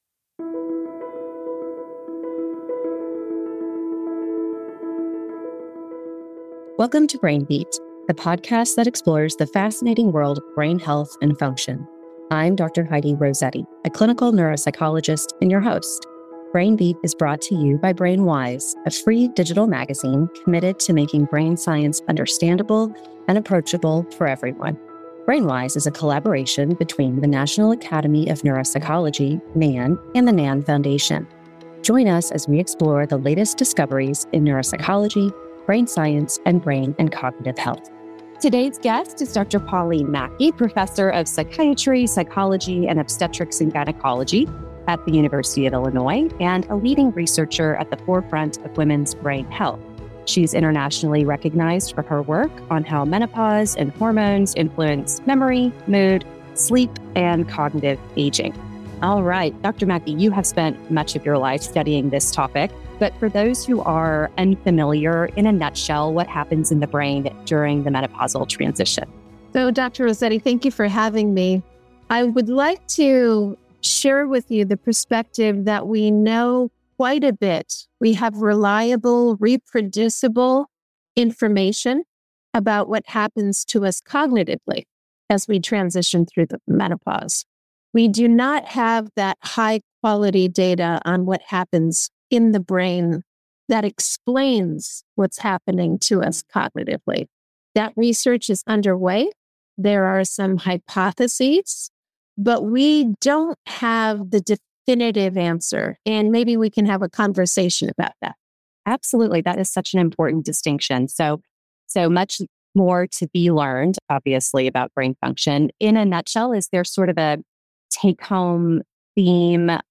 The conversation also stresses the critical health disparities faced by Black and Brown women, who often endure more severe and prolonged symptoms, underscoring the need for personalized care.